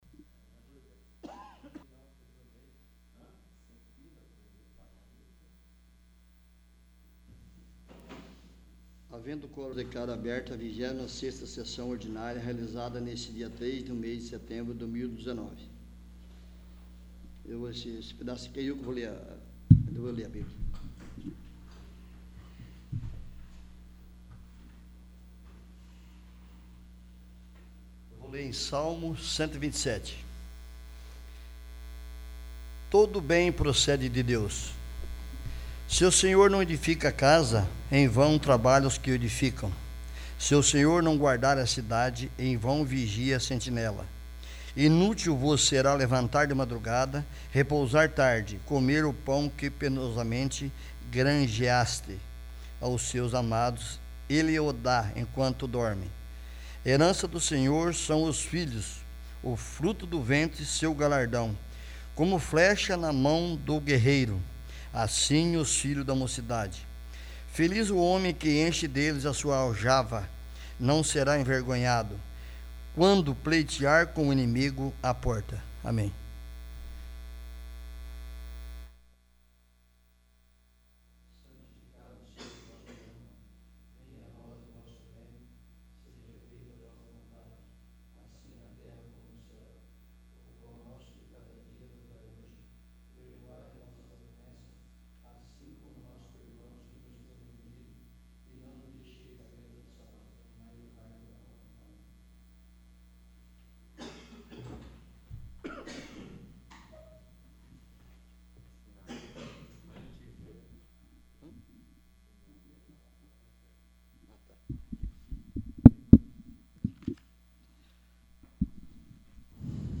26º. Sessão Ordinária